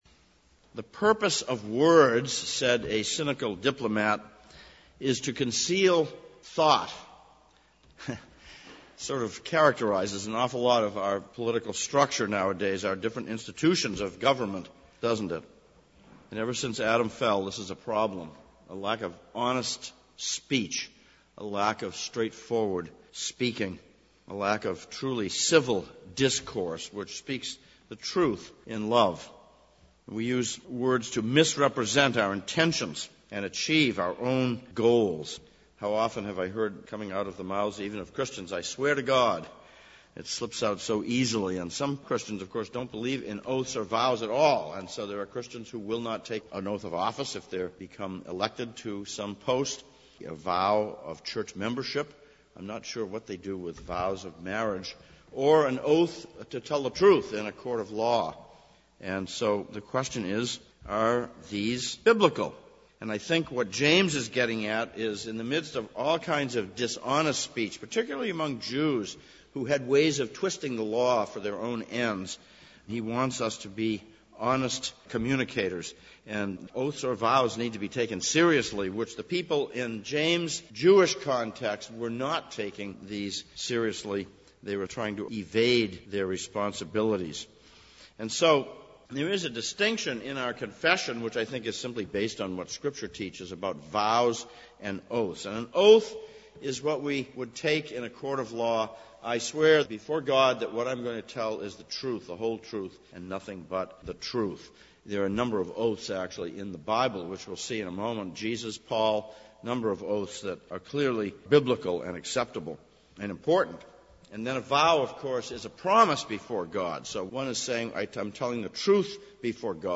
Leviticus 19:1-12 Service Type: Sunday Evening Sermon text